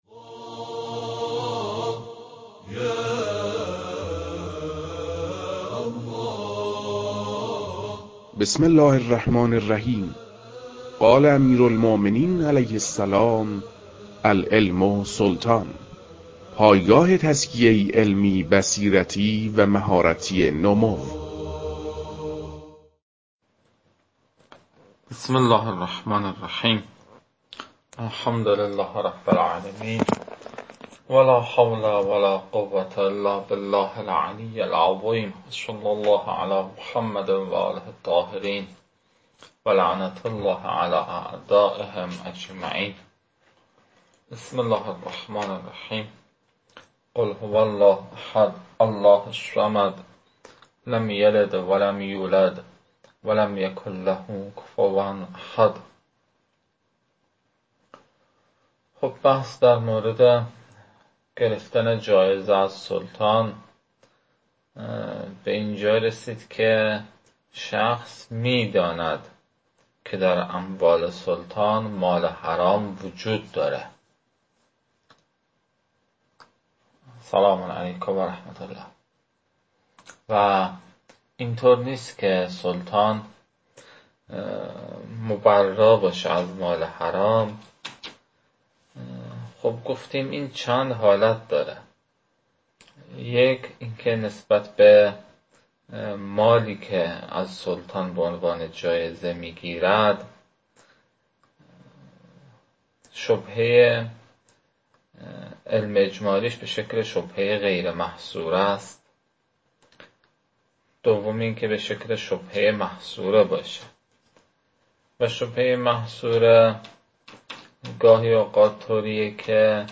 در این بخش، فایل های مربوط به تدریس مبحث المسألة الثانية جوائز السلطان و عمّاله از خاتمه كتاب المكاسب متعلق به شیخ اعظم انصاری رحمه الله